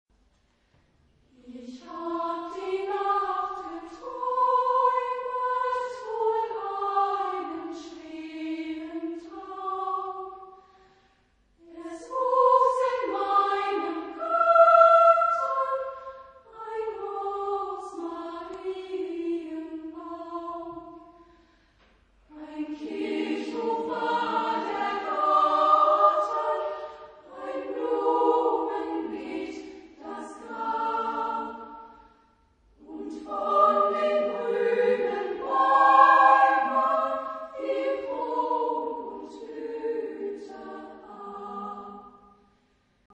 Genre-Stil-Form: romantisch ; Kinder ; Lied ; weltlich
Chorgattung: SSA  (3-stimmiger Kinderchor ODER Frauenchor )
Tonart(en): g-moll
Aufnahme Bestellnummer: 7. Deutscher Chorwettbewerb 2006 Kiel